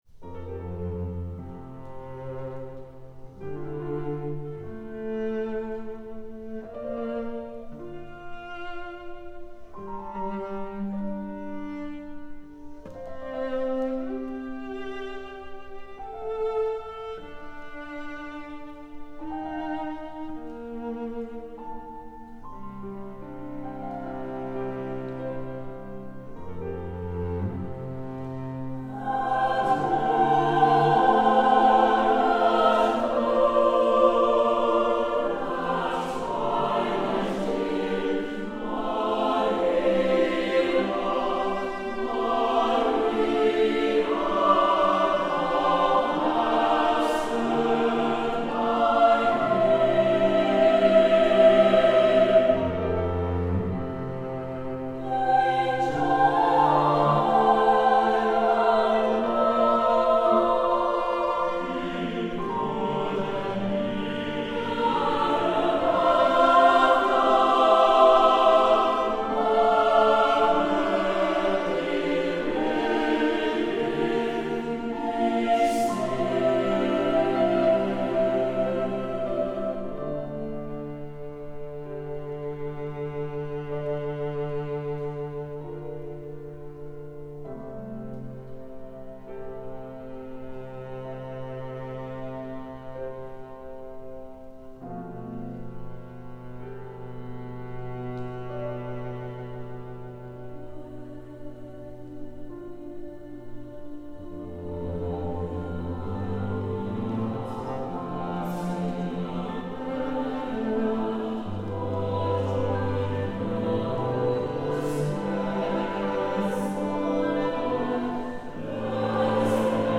SATB Chorus with divisi, Piano, & Cello